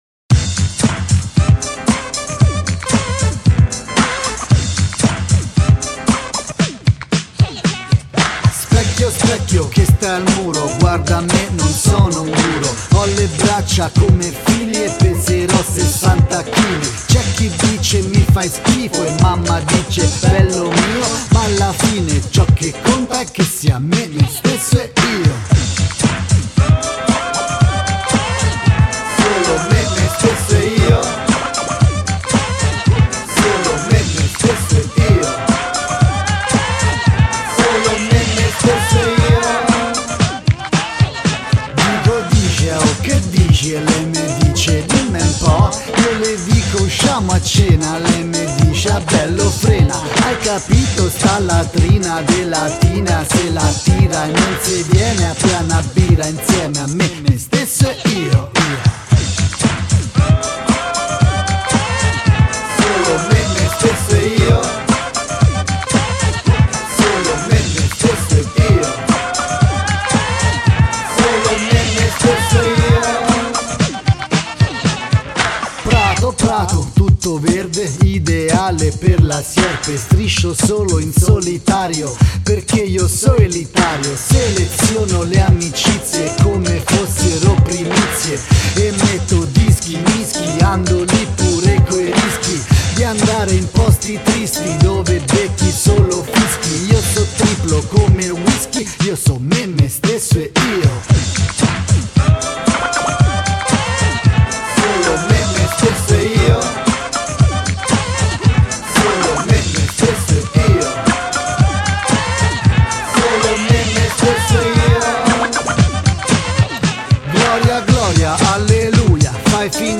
Registrato presso lo studio di Roma